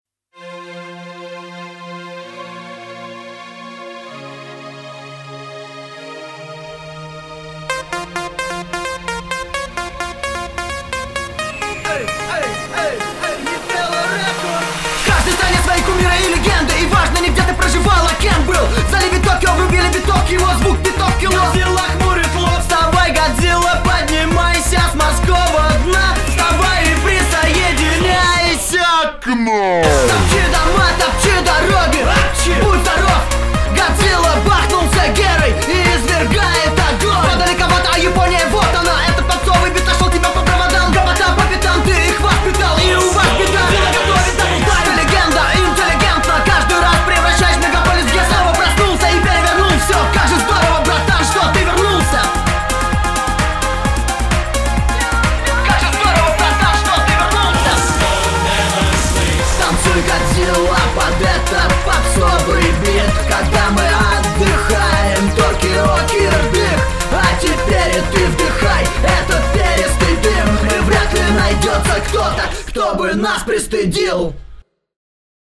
просто минуса клубные и напевчики есть)